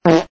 Fart Sound Effect Free Download
Fart